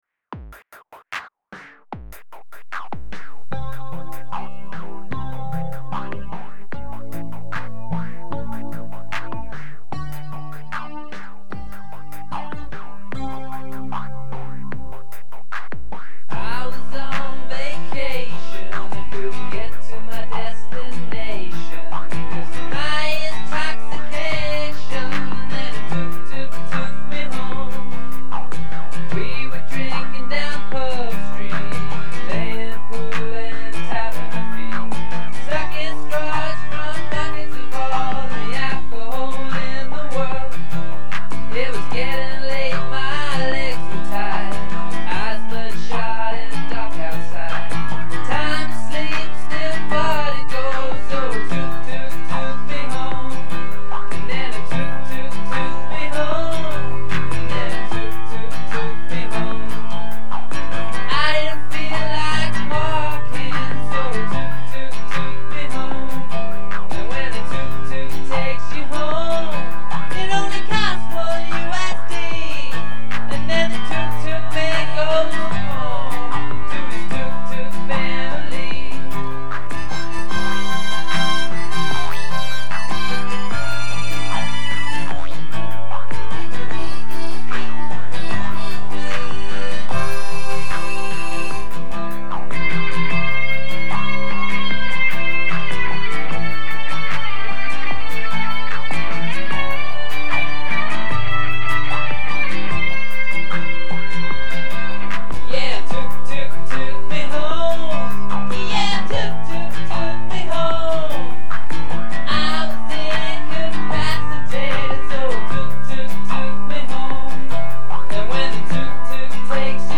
a super silly folk ditty
And pretty catchy on account of its simplicity.